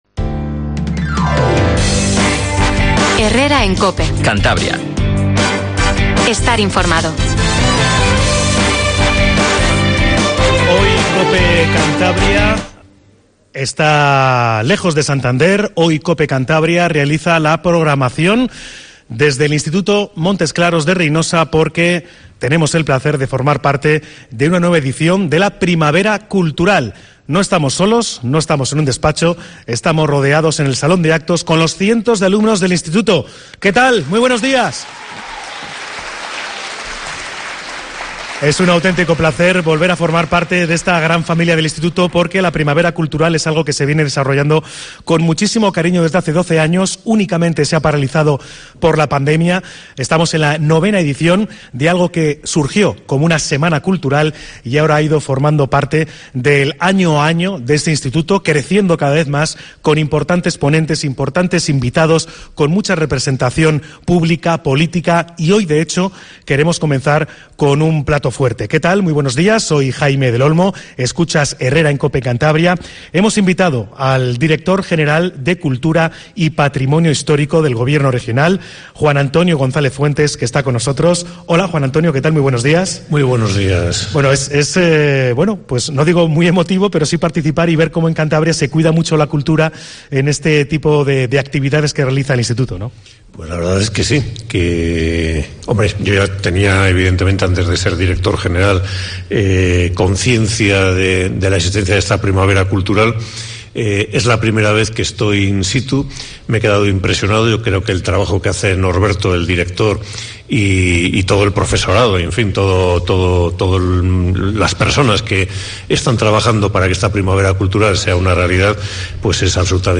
Cope Cantabria visita el IES Montesclaros con motivo de la "Primavera Cultural" que cumple su novena edición
Entrevista a Juan Antonio González Fuentes, Director General de Cultura y Patrimonio Histórico